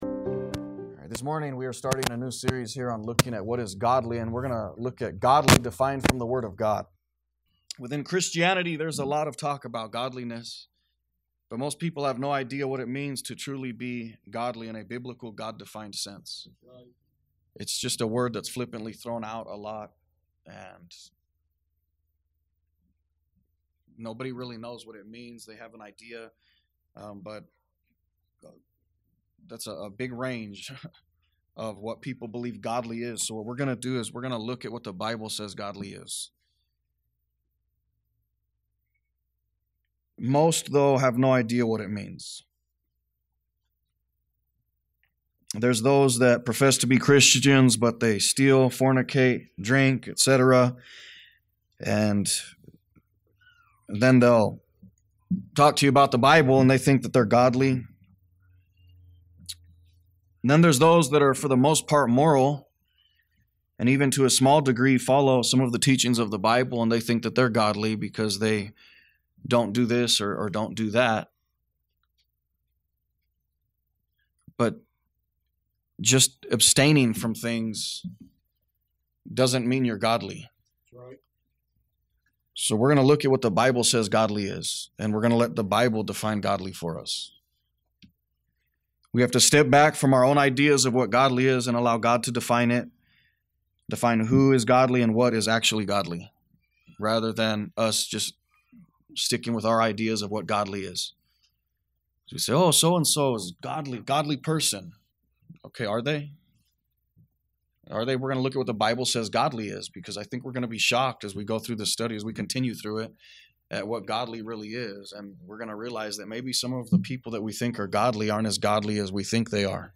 A message from the series "Stand Alone Messages."